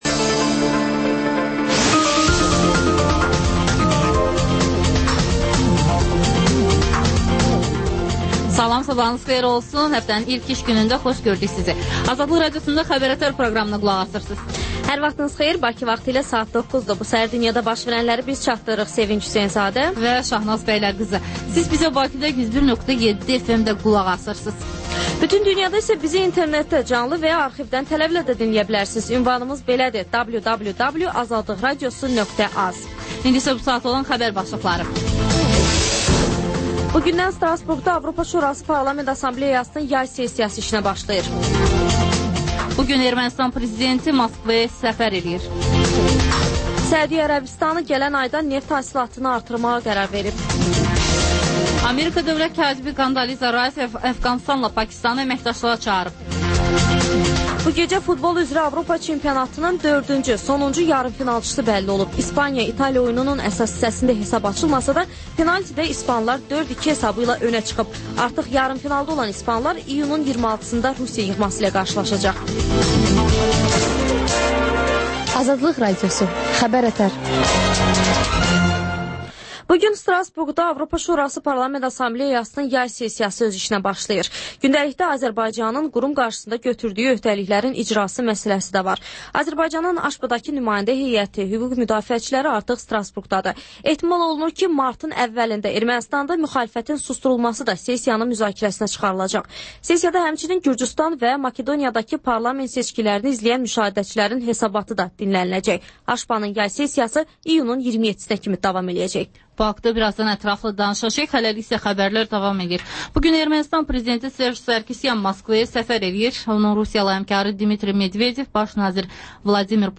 Xəbərlər, müsahibələr.